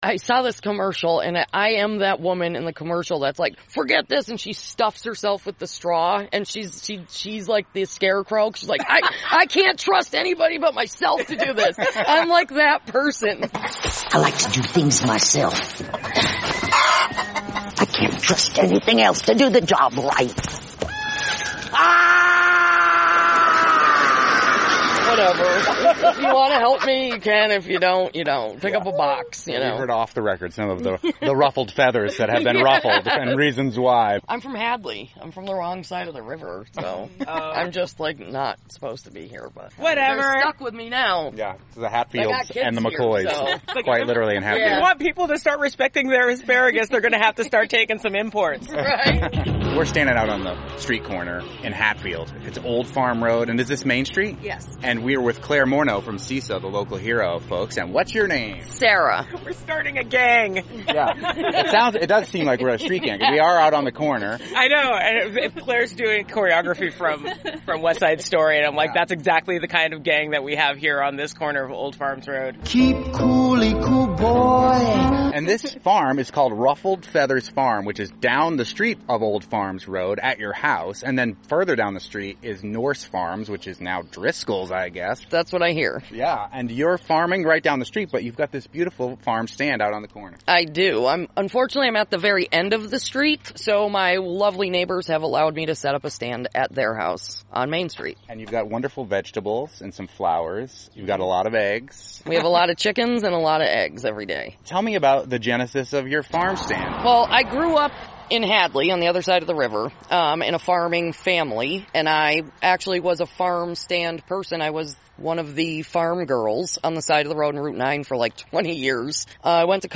This program aired on NEPM on July 2, 2025.